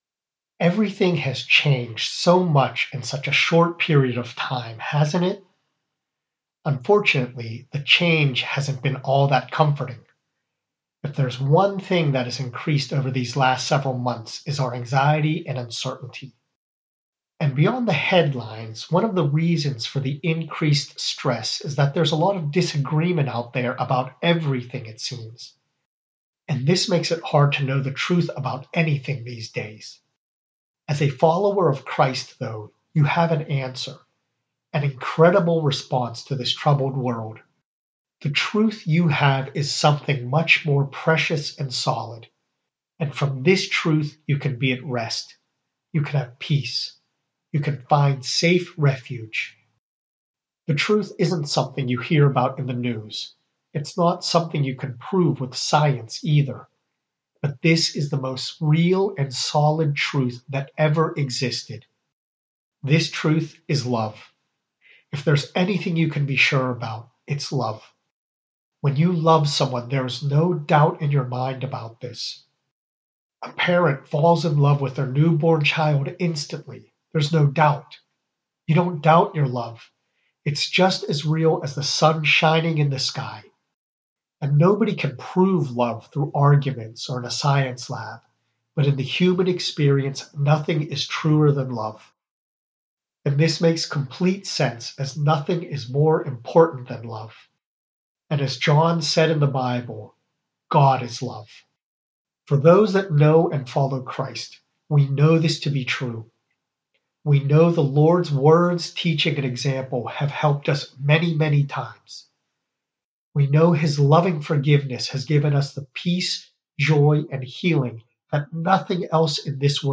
Audio message about this prayer